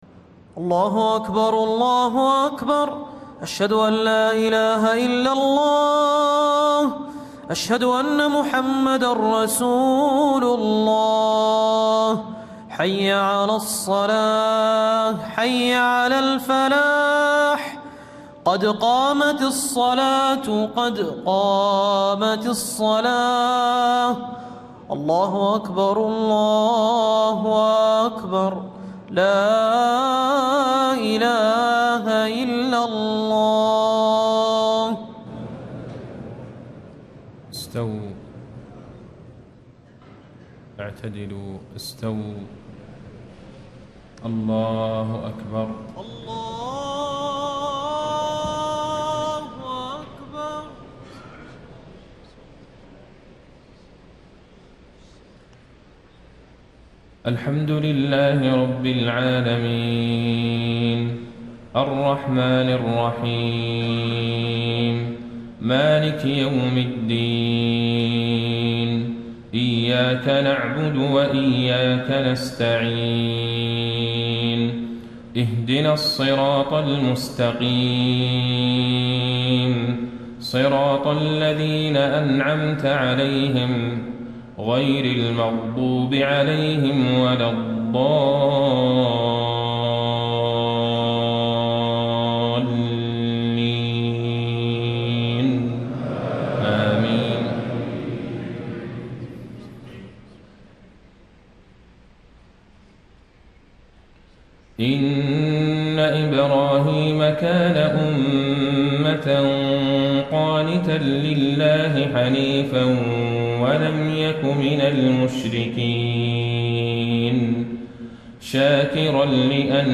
فجر 24 رمضان ١٤٣٥ من سورة النحل و الكهف > 1435 🕌 > الفروض - تلاوات الحرمين